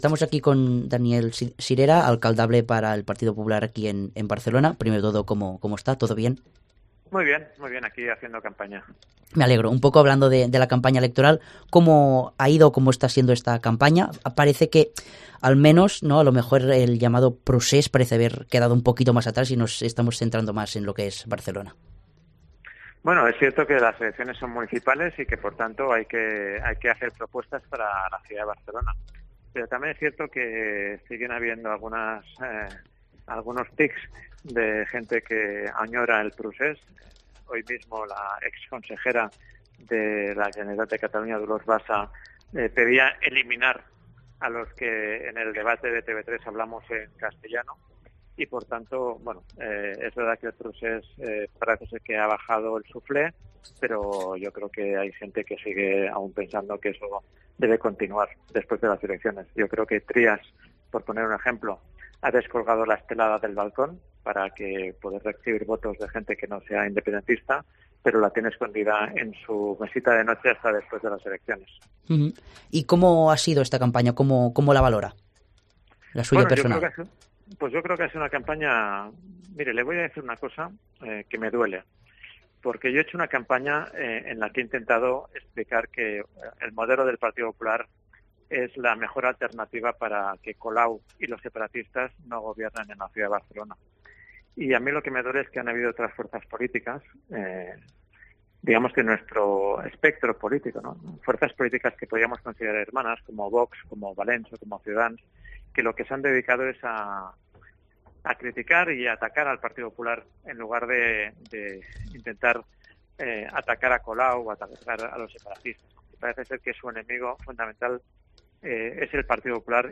Entrevista a Daniel Sirera, candidato del PP en el Ayuntamiento de Barcelona